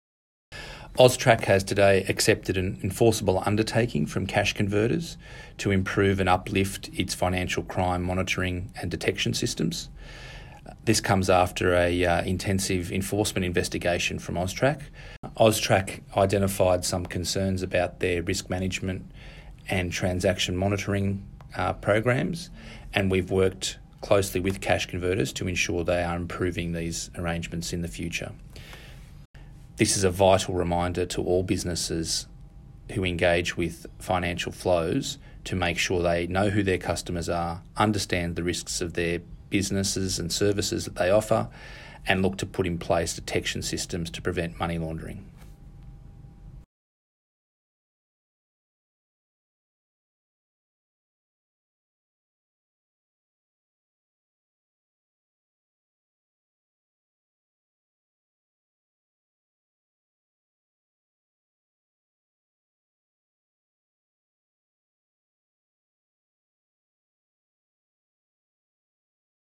Hear from AUSTRAC's acting CEO
AUSTRAC's acting CEO, Peter Soros, speaks about the Cash Converters EU.